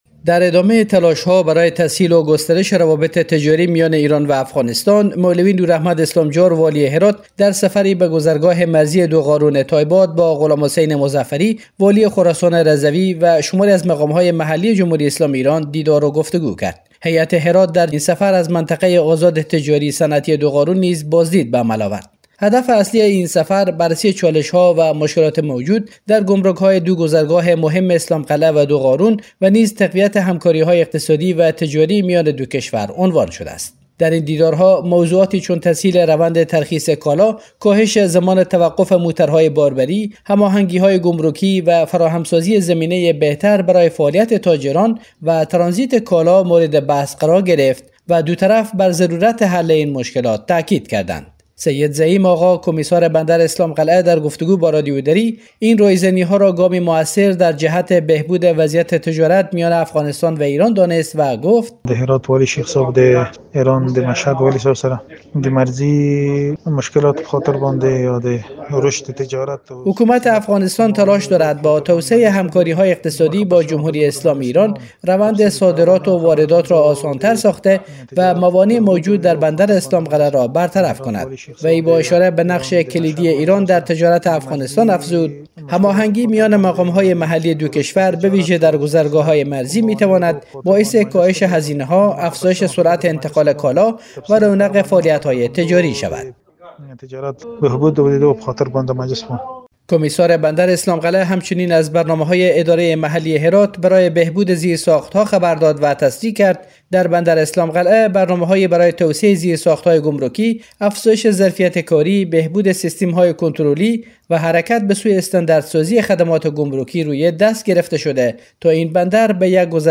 سید زعیم‌آغا کمیسار بندر اسلام‌قلعه در گفت‌وگو با رادیو دری این رایزنی‌ها را گامی مؤثر در جهت بهبود وضعیت تجارت میان افغانستان و ایران دانسته و گفت: حکومت افغانستان تلاش دارد با توسعه همکاری‌های اقتصادی با جمهوری اسلامی ایران، روند صادرات و واردات را آسان‌تر ساخته و موانع موجود در بندر اسلام‌قلعه را برطرف کند.